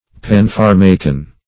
Search Result for " panpharmacon" : The Collaborative International Dictionary of English v.0.48: Panpharmacon \Pan*phar"ma*con\, n. [NL.
panpharmacon.mp3